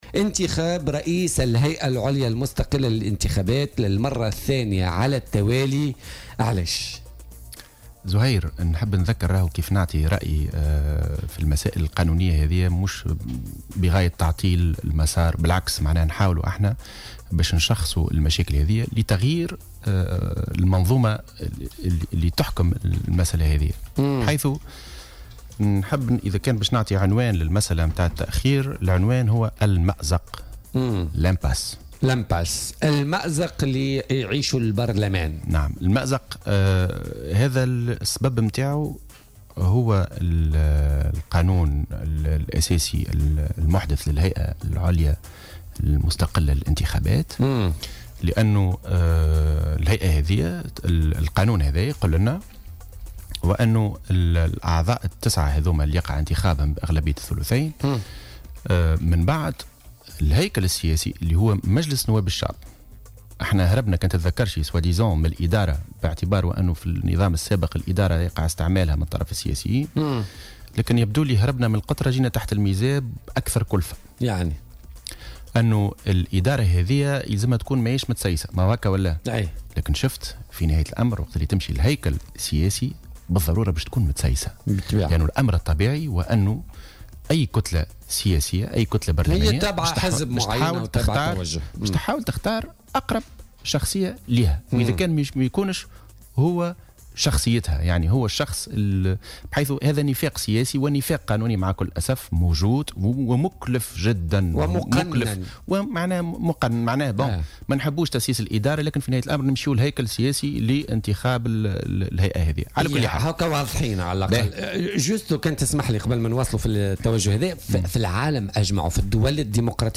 وقال في مداخلة له اليوم في برنامج "بوليتيكا" إن المأزق مردّه وجود ثغرات في القانون الأساسي المحدث لهيئة الانتخابات، من ذلك انتخاب أعضاء الهيئة ورئيس الهيئة من قبل مجلس نواب الشعب وهو طريق صعب ومكلف، وفق تعبيره.